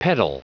Prononciation du mot pedal en anglais (fichier audio)
Prononciation du mot : pedal